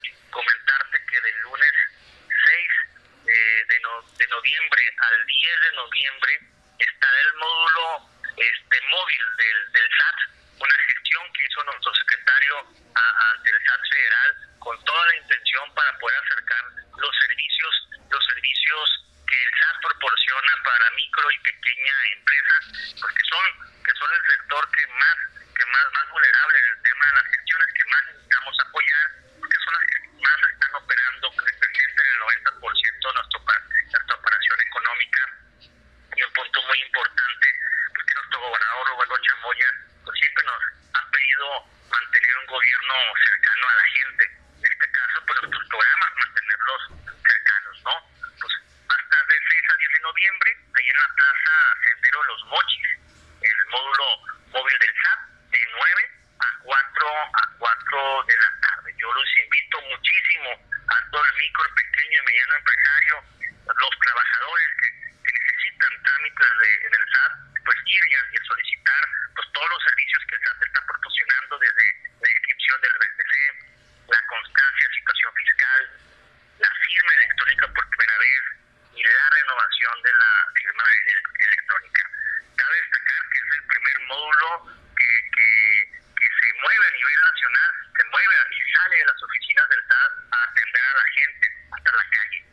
Ahuizotl-López-Apodaca-subsecretario-de-Fomento-Económico.mp3